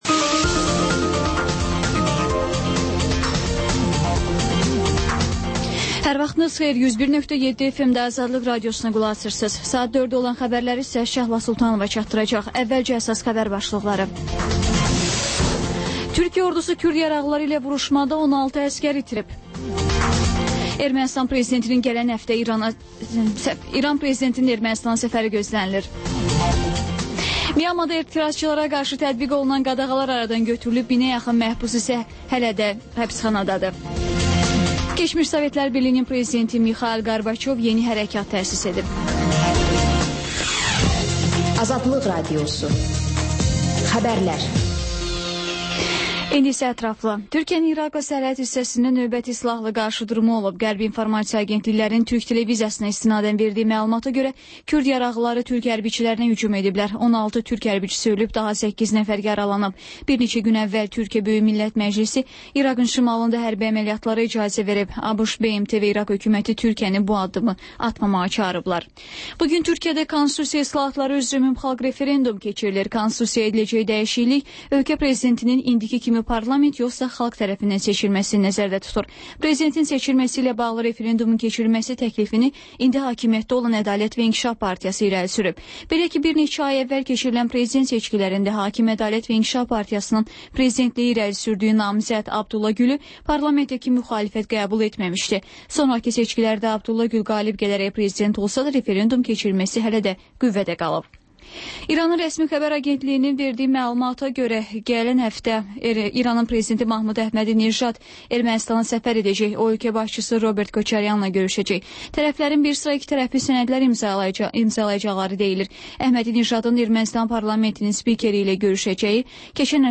radioşoularında